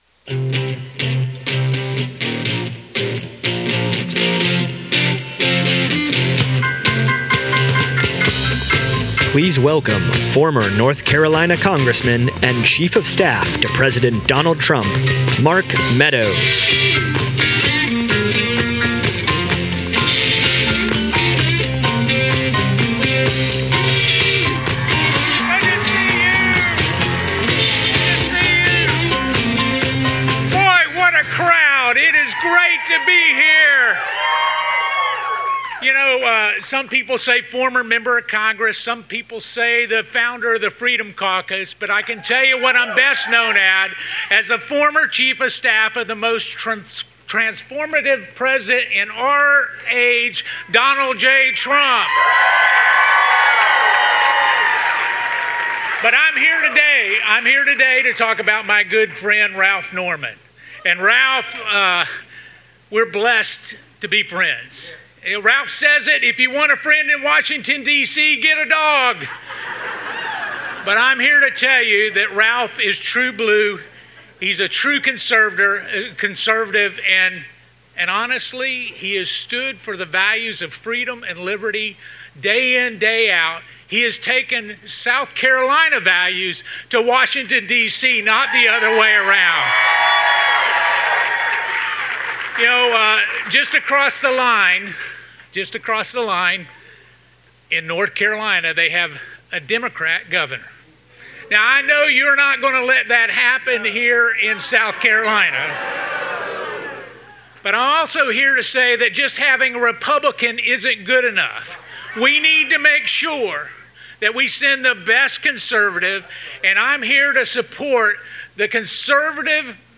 AUDIO: Ralph Norman For Governor Announcement